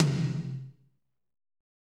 Index of /90_sSampleCDs/Northstar - Drumscapes Roland/DRM_Fast Rock/TOM_F_R Toms x
TOM F RHI0ML.wav